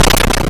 M1_BreakBlock.wav